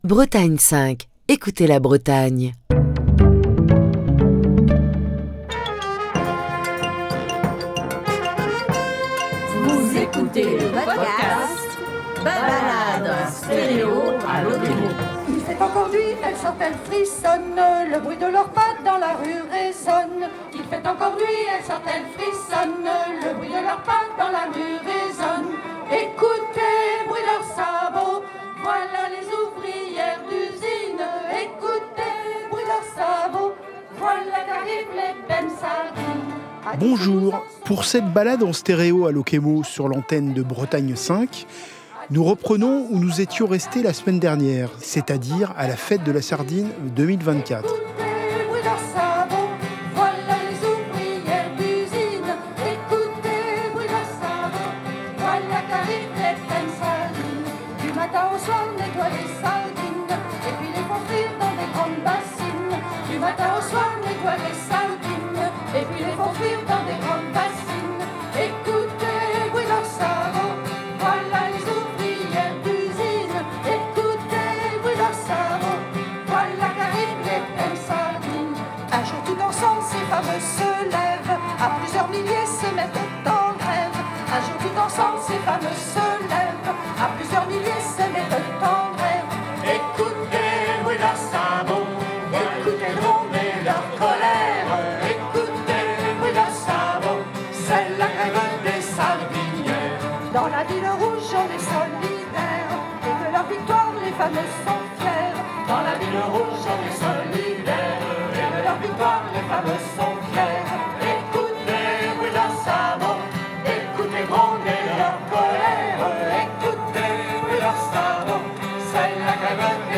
Ce vendredi, nous poursuivons cette Balade en stéréo à Lokémo à la fête de la sardine, où nous trouvions la semaine dernière...